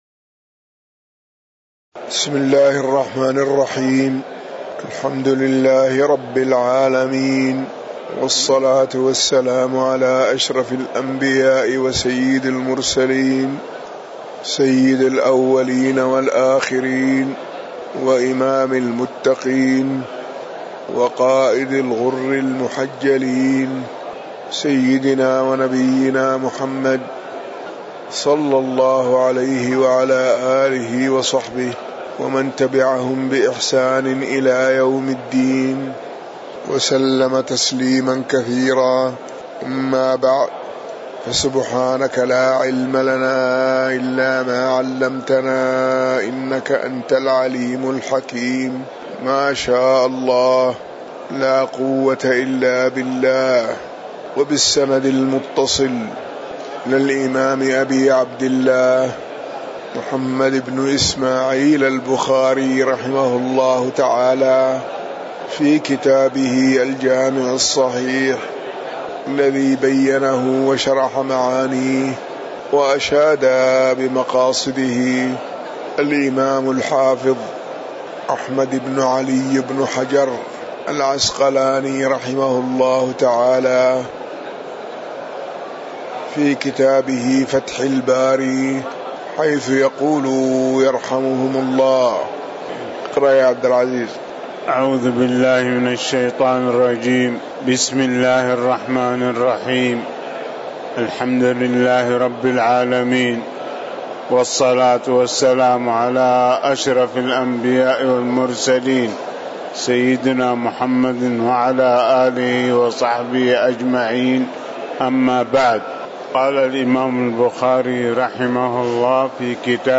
تاريخ النشر ١ صفر ١٤٤١ هـ المكان: المسجد النبوي الشيخ